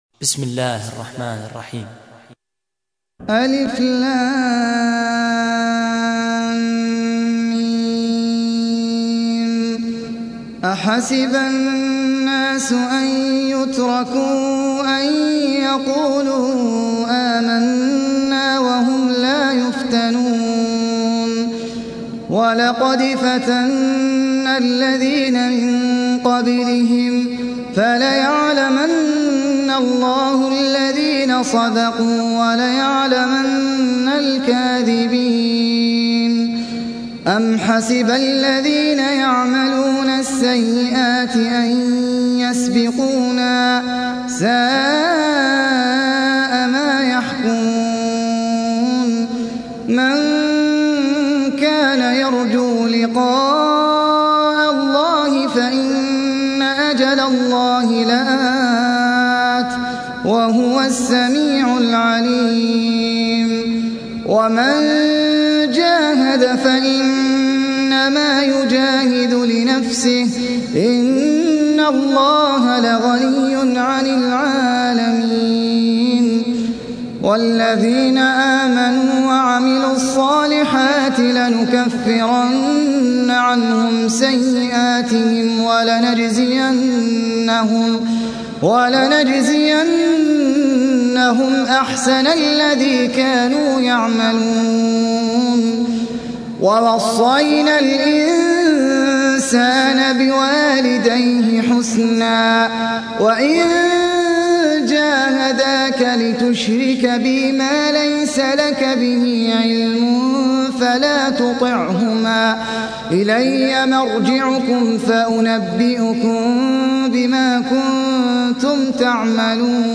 سورة العنكبوت | القارئ أحمد العجمي